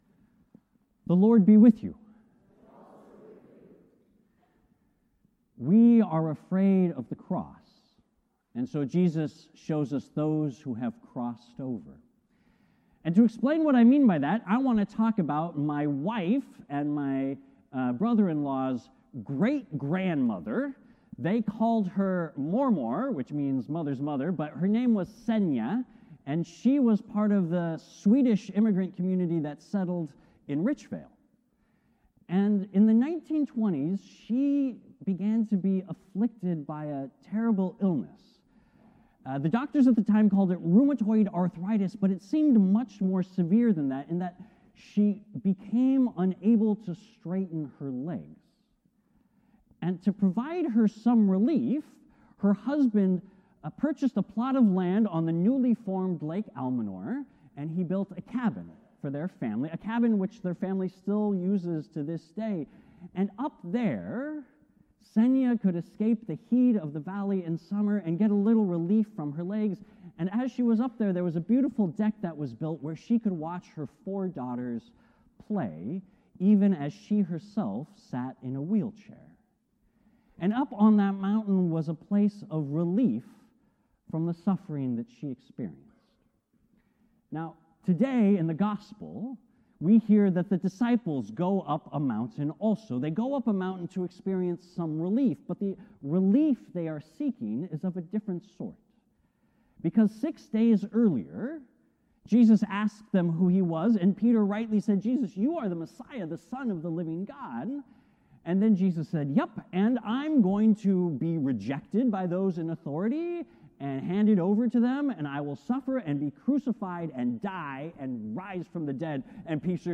As you listen to the sermon, here are some questions you can reflect on to deepen your spiritual connection with God: